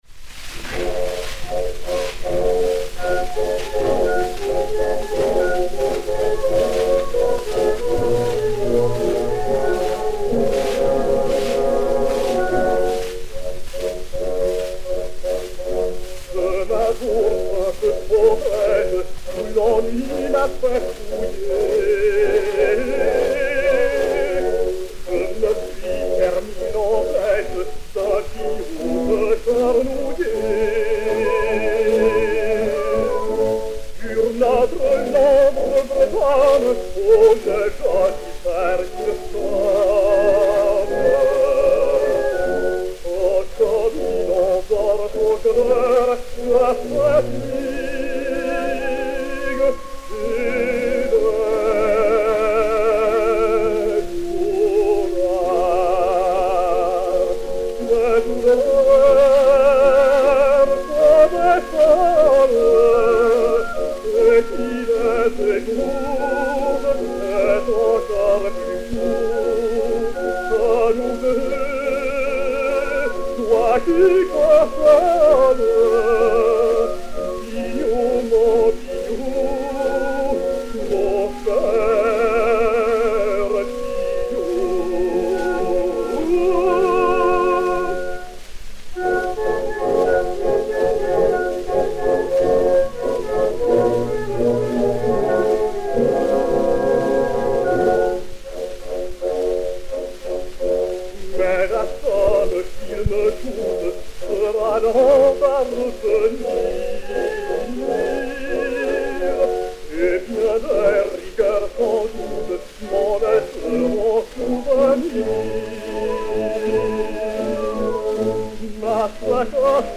chanson bretonne (par.
Albert Vaguet et Orchestre